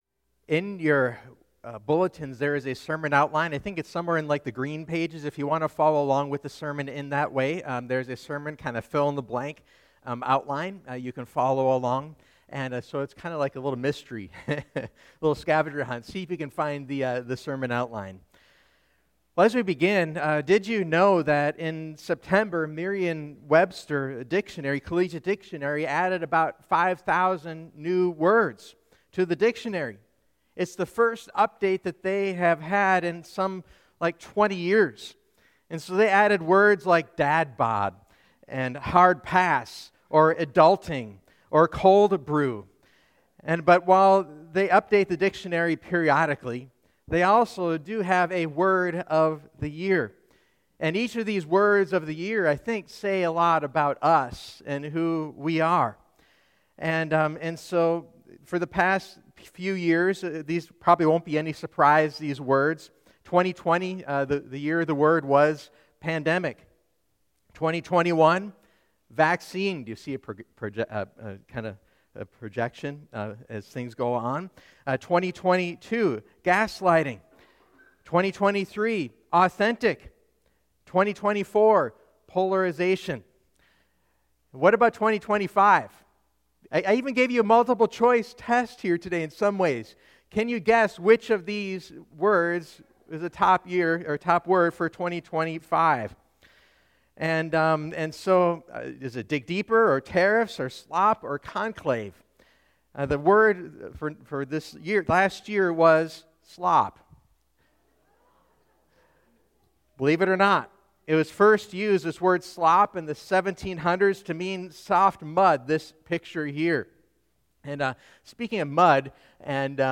Join us for our series “All Things New” where we rediscover God’s purpose for our lives. Here are the upcoming sermons in the series: